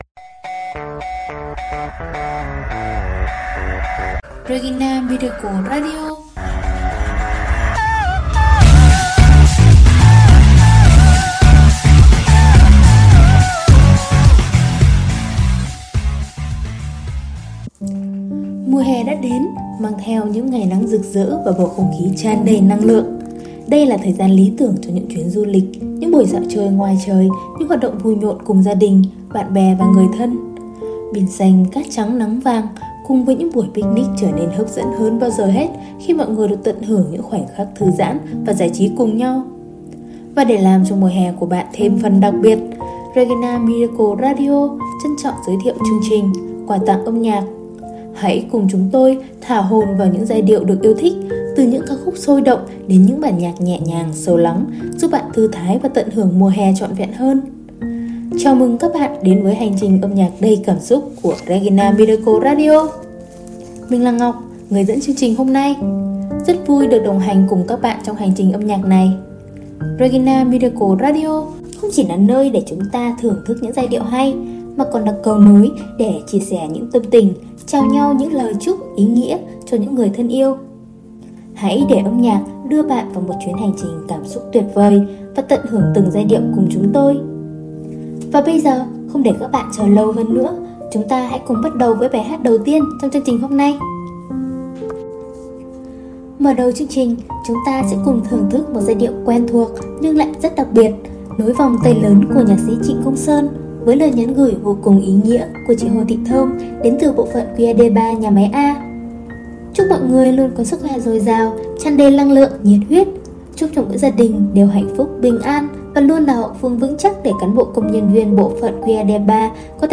Mùa hè rực rỡ đã đến – mang theo những thanh âm sôi động, những lời chúc ý nghĩa và thật nhiều cảm xúc yêu thương!